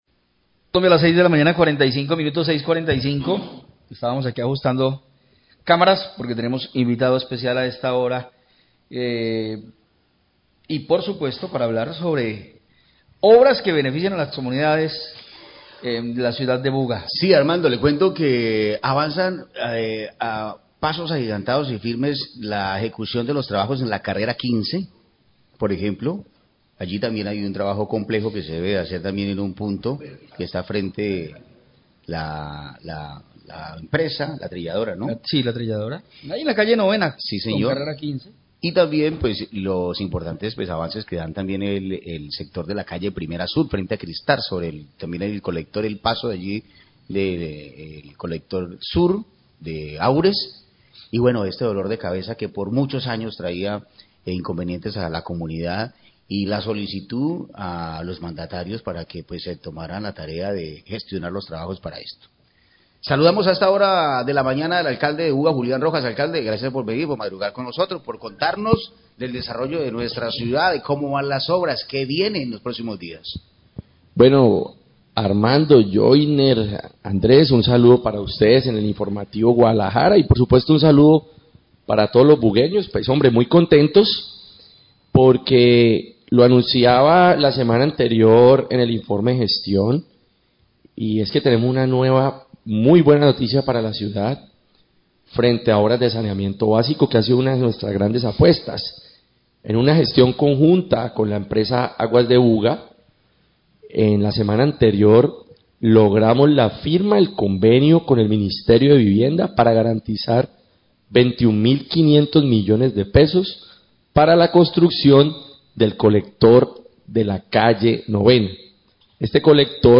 Radio
El Alcalde de Buga, Julián Rojas, habla del informe de gestión presentado en días pasados y destaca los recursos para el colector de la Calle 9a que hace parte de las obras complementarias para la PTAR de Buga. Agradece a la CVC y la Gobernación del Valle por la consecución de recursos para la PTAR de la ciudad.